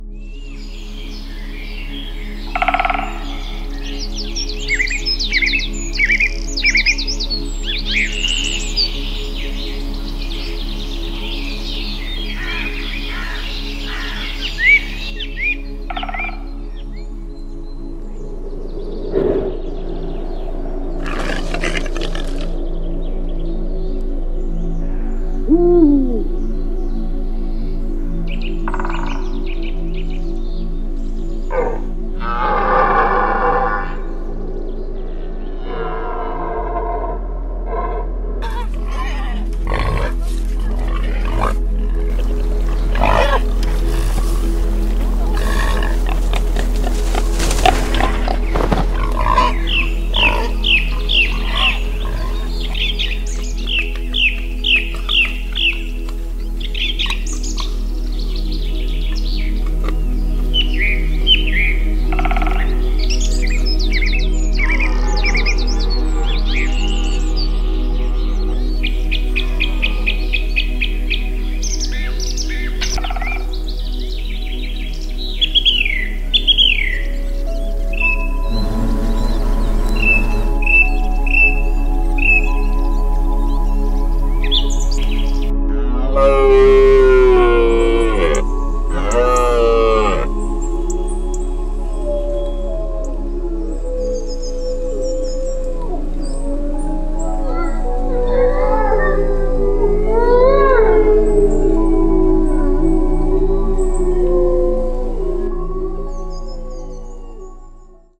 Waldgeräusche